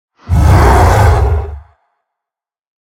Minecraft Version Minecraft Version snapshot Latest Release | Latest Snapshot snapshot / assets / minecraft / sounds / mob / ravager / roar1.ogg Compare With Compare With Latest Release | Latest Snapshot
roar1.ogg